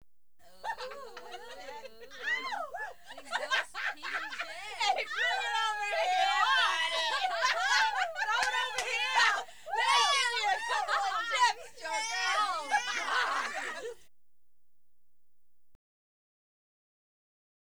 Laughter Female Party Screams Sound Effect
Download a high-quality laughter female party screams sound effect.
laughter-female-party-screams.wav